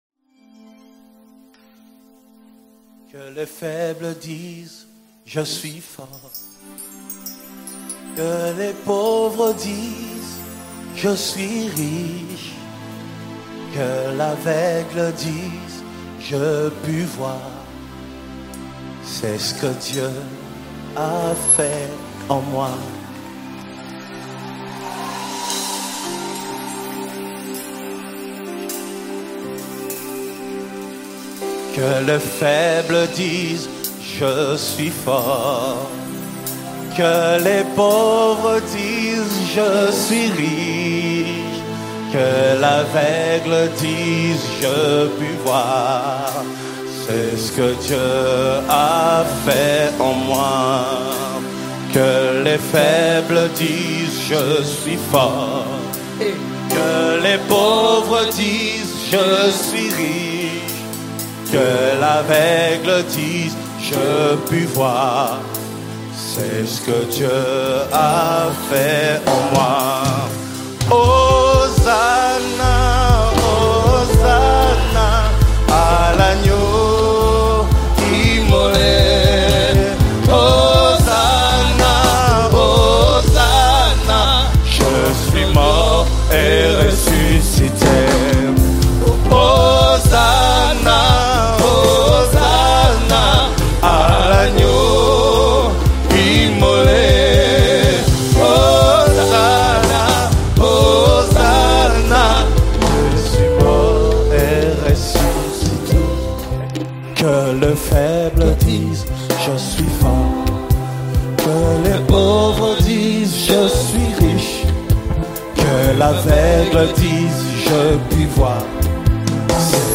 INSPIRATIONAL WORSHIP ANTHEM